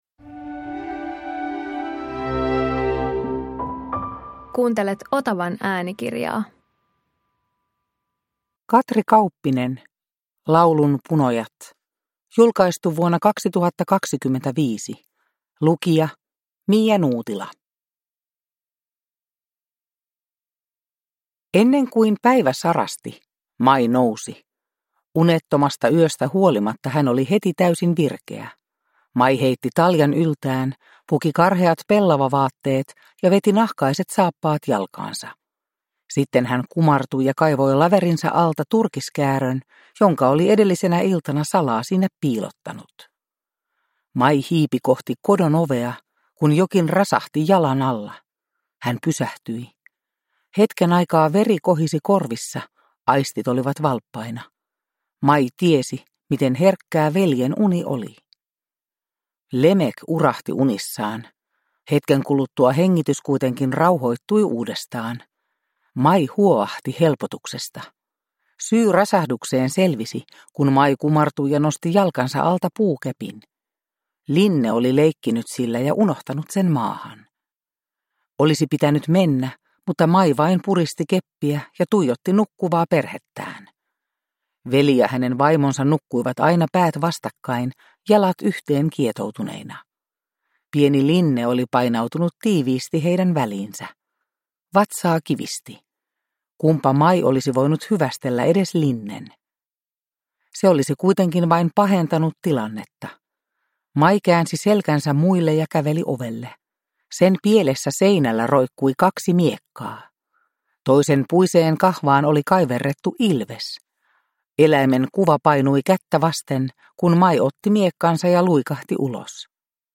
Laulunpunojat – Ljudbok